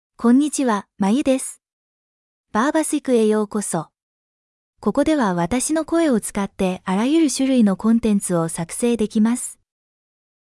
MayuFemale Japanese AI voice
Mayu is a female AI voice for Japanese (Japan).
Voice sample
Female
Mayu delivers clear pronunciation with authentic Japan Japanese intonation, making your content sound professionally produced.